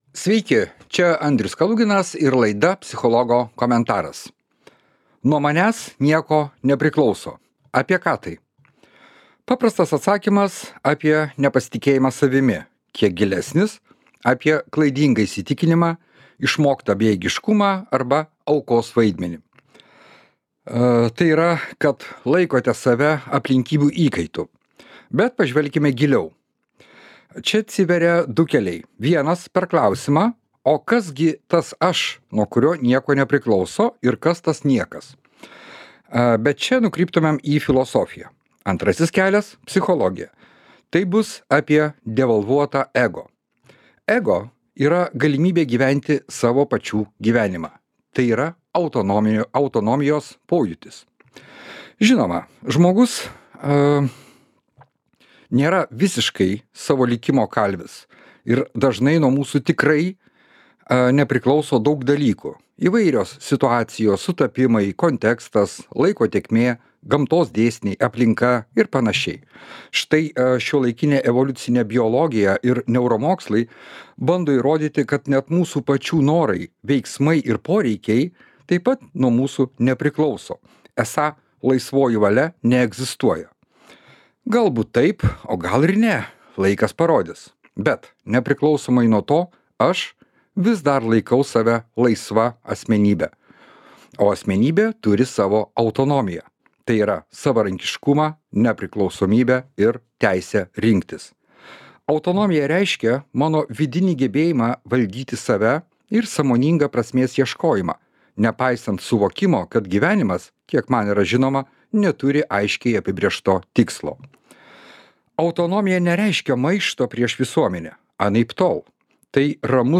Psichologo komentaras